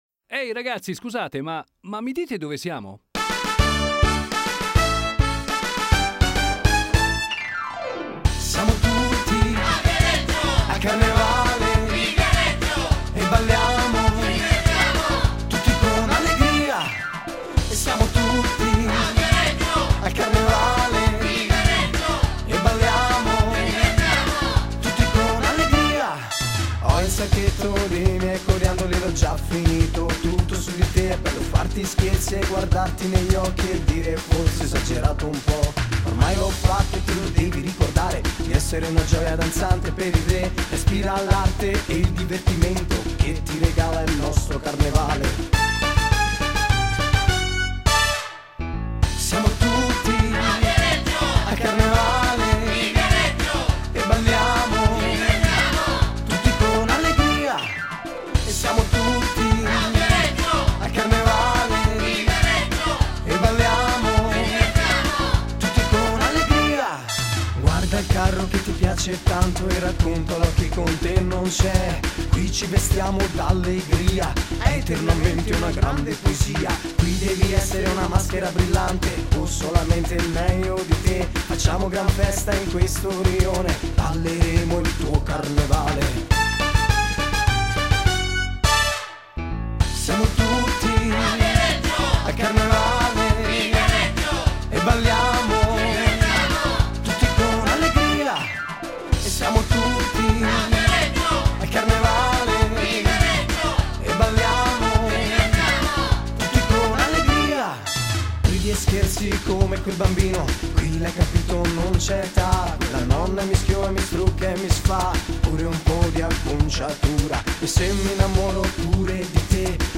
La canzone è molto orecchiabile e coinvolgente.